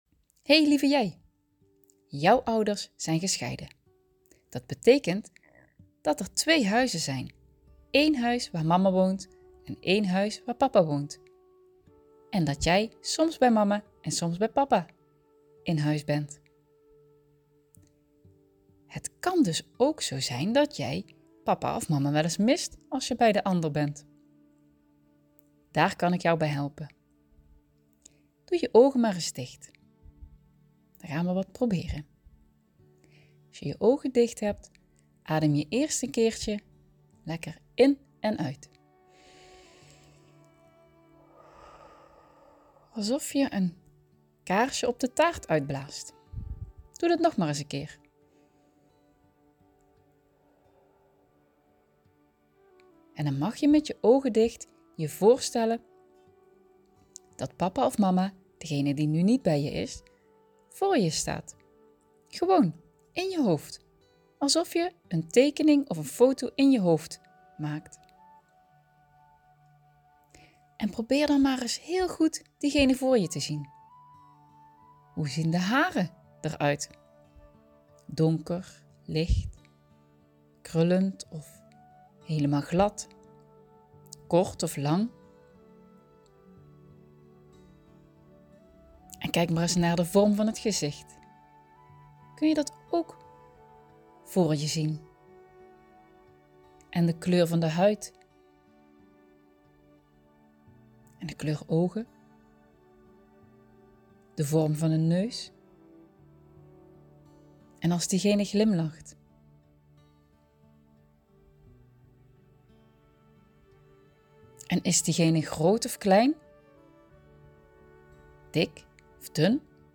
Tijd voor een verhaaltje - Kids meditatie Je kunt de Kids Meditatie van de Scheidingsknuffel hier downloaden, zodat je kind het overal kan beluisteren op telefoon, tablet of laptop.